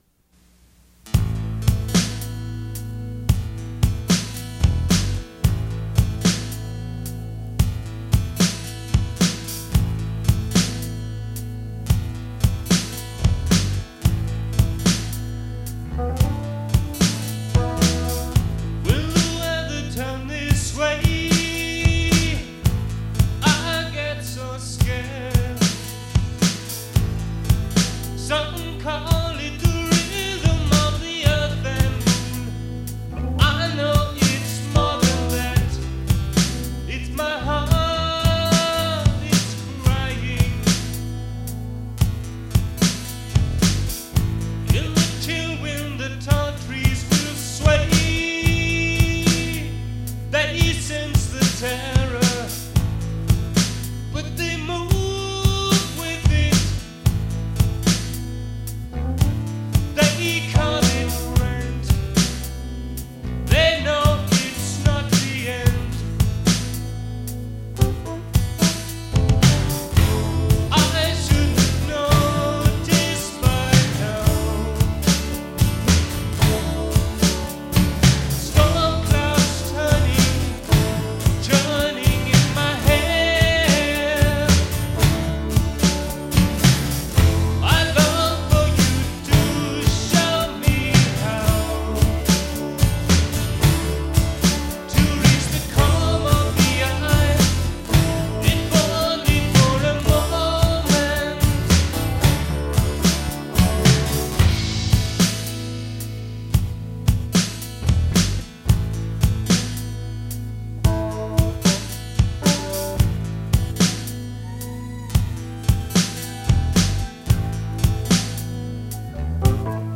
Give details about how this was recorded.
A modestly ambitious (at least, for this amateur musician and would-be recording studio engineer) 4-track home recording of some pieces quite on my mind at the time. The master tape had deteriorated somewhat over the years prior to its eventual transfer to digital media in the mid-2000s.